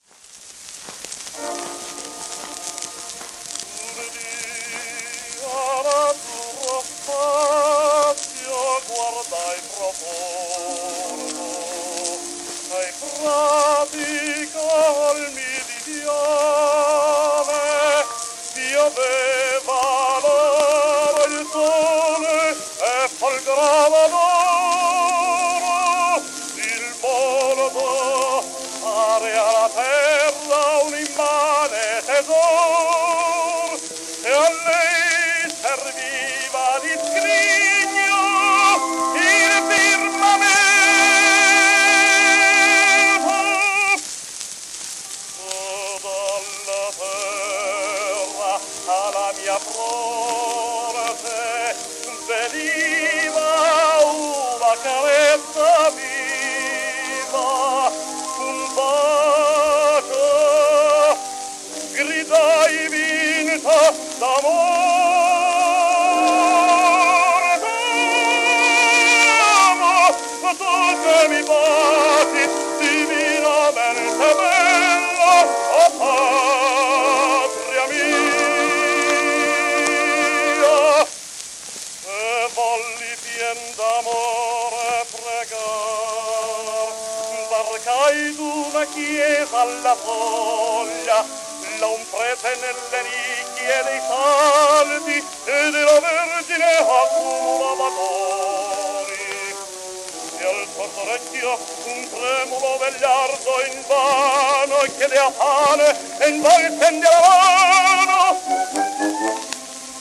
w/ オーケストラ
12インチ片面盤
盤質B+/B *軽い溝荒れ、薄い擦れと小キズ
1907年録音
旧 旧吹込みの略、電気録音以前の機械式録音盤（ラッパ吹込み）